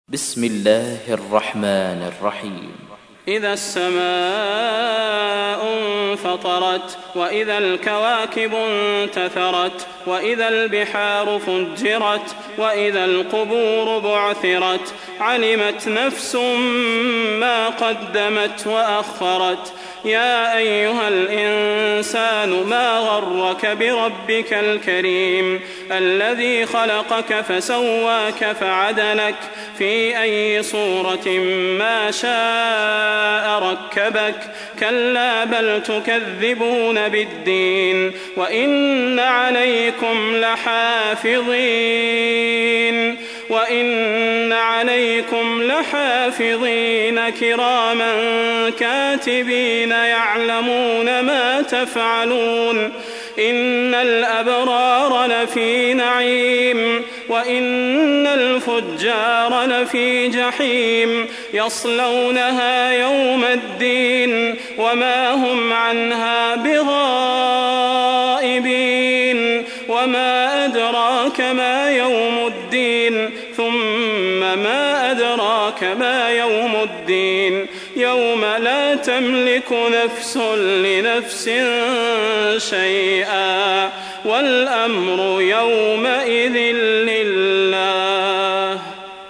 تحميل : 82. سورة الانفطار / القارئ صلاح البدير / القرآن الكريم / موقع يا حسين